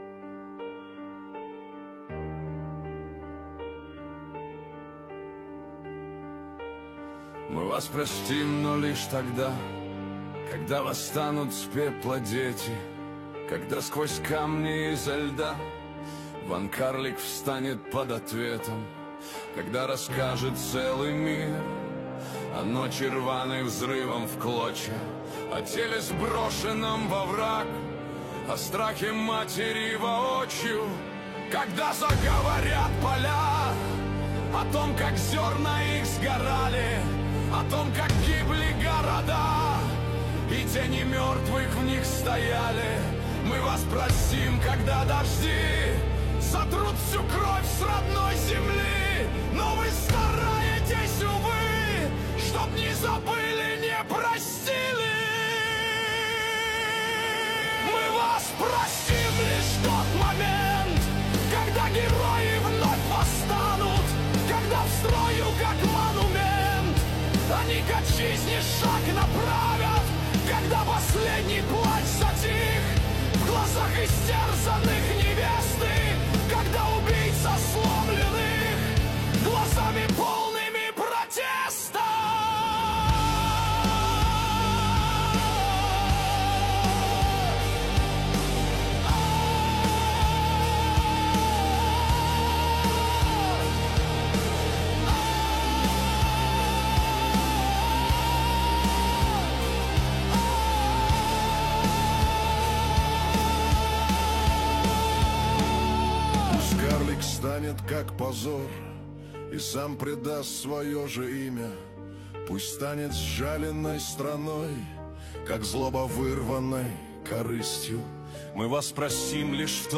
15 декабрь 2025 Русская AI музыка 99 прослушиваний